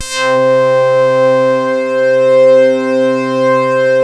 BAND PASS .4.wav